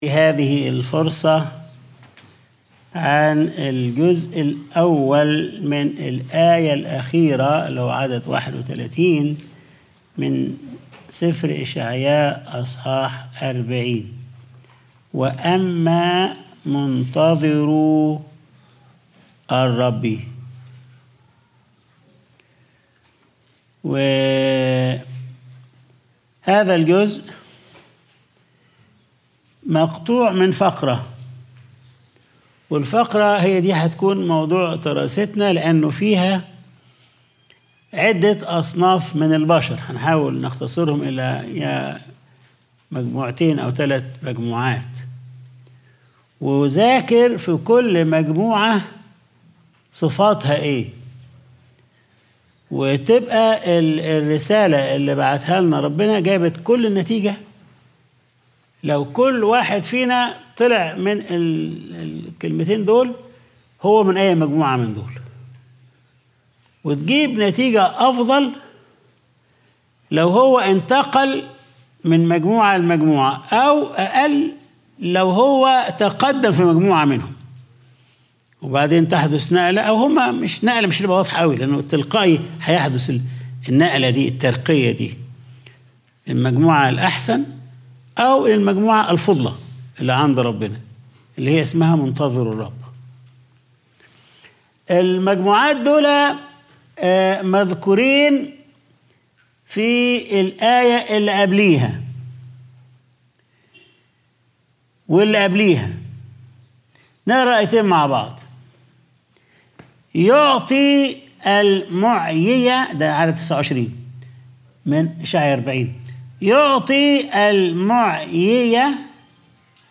Sunday Service | وأما منتظروا الرب